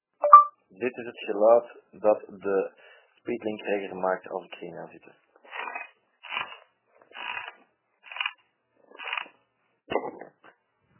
Kraak, piep: help!
Waar ik me tot nu toe het meest aan ergerde bij de Regger, zijn de kraakgeluiden.
Elke keer ik me in de stoel plofte, of eruit stapte, ging dat gepaard met het nodige gekraak en gepiep.
speedlinkreggersound.mp3